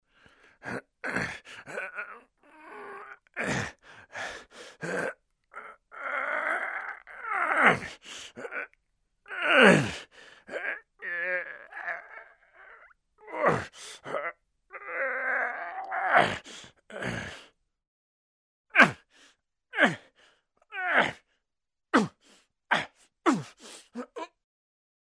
Стонет, мучается мужчина